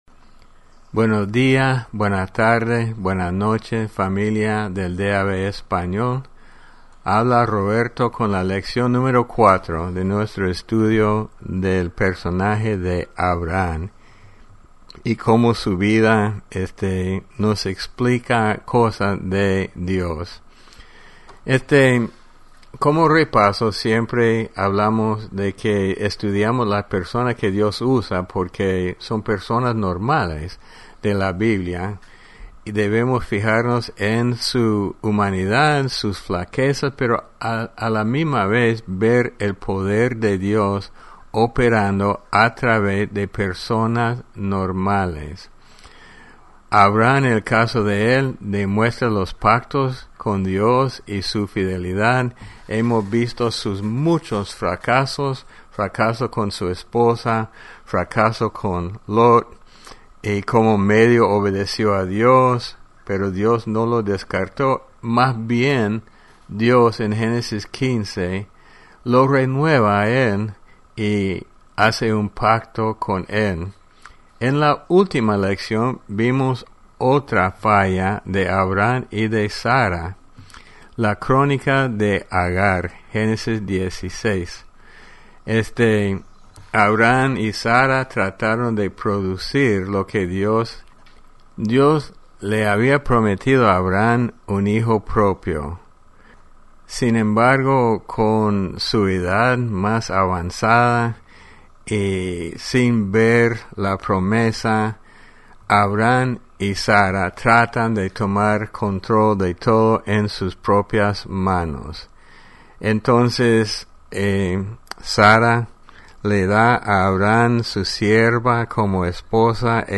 Lección #4 Abraham – El Padre inseguro de la Fe